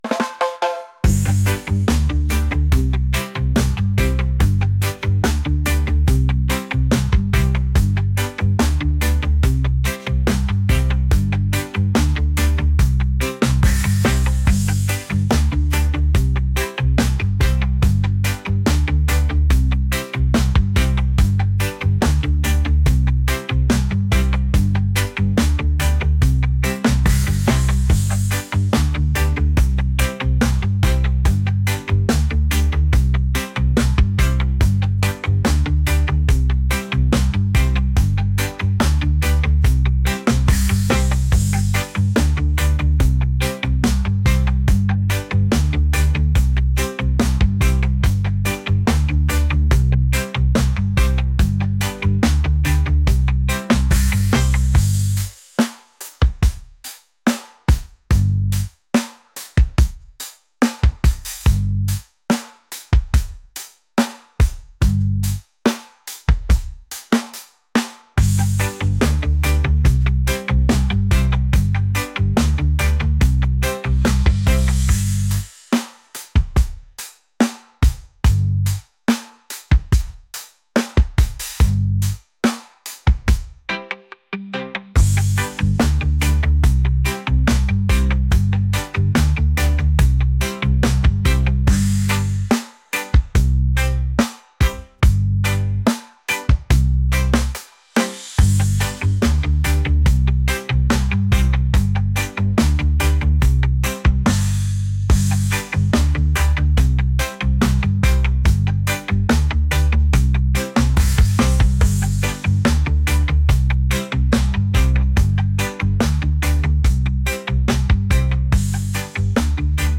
groovy | upbeat | reggae